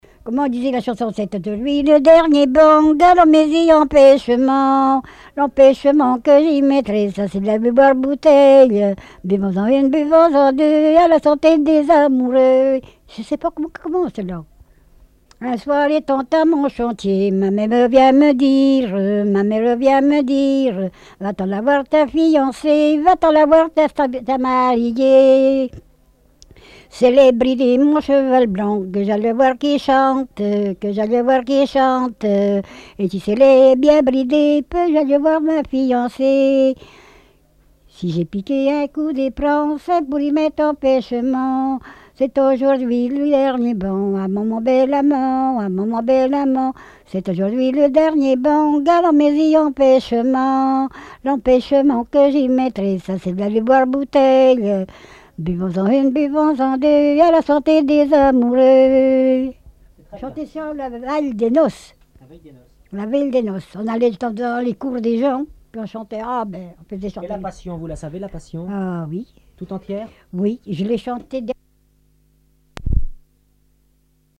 Répertoire de chants brefs et traditionnels
Pièce musicale inédite